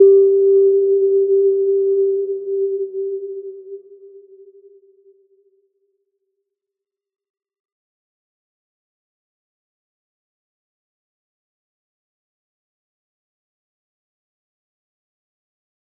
Little-Pluck-G4-p.wav